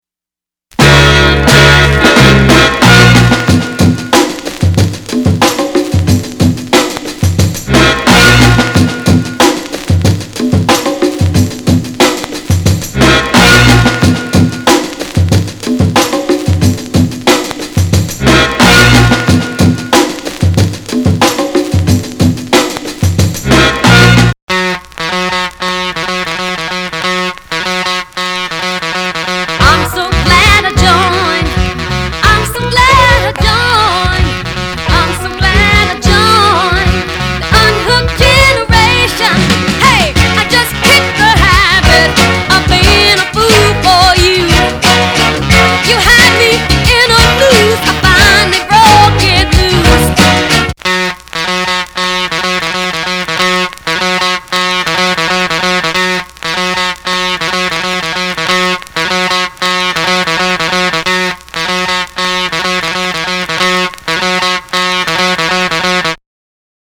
R&B、ソウル
（イントロ・エディット）→